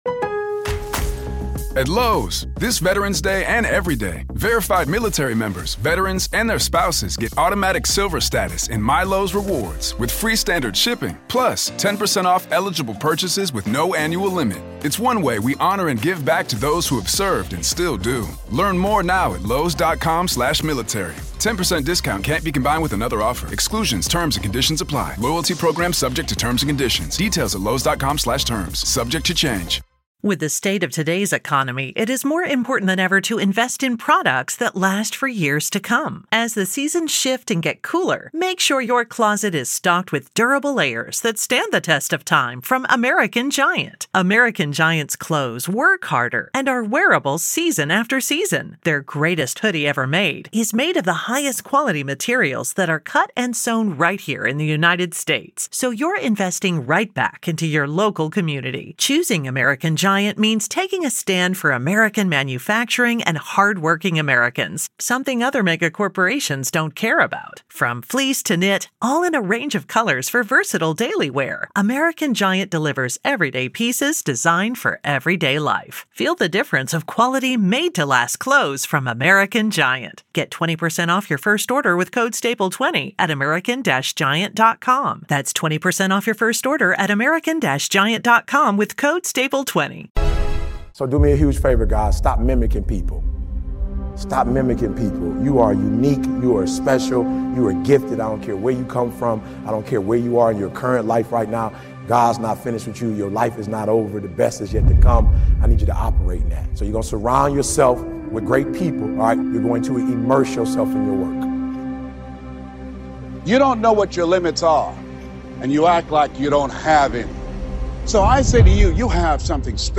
Wake up determined, go to bed satisfied! Your morning determines your day! One of the BEST MORNING MOTIVATIONAL VIDEOS featuring speeches by Eric Thomas